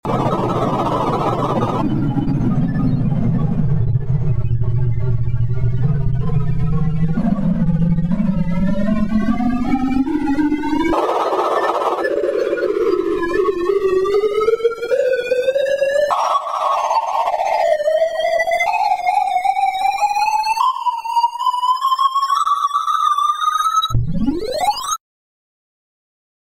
Sounds like a horror movie sound effects free download